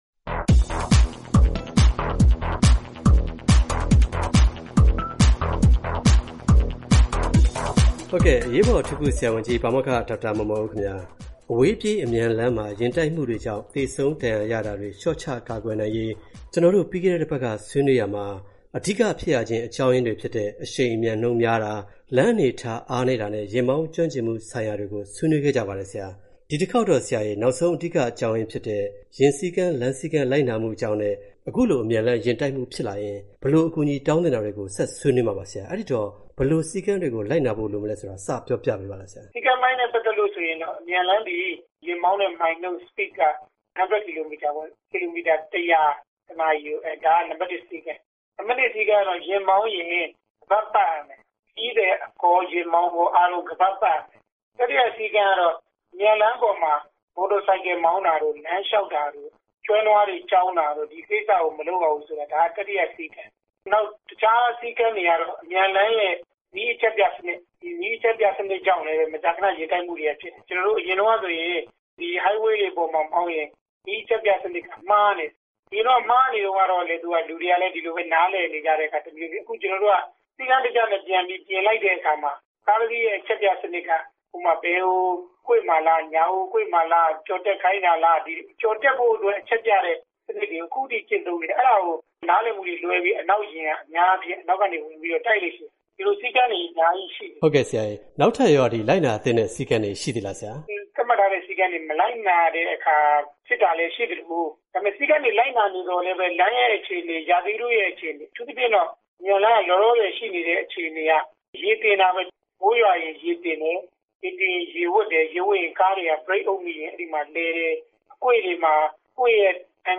ဆက်သွယ်မေးမြန်း ဆွေးနွေးတင်ပြထားပါတယ်။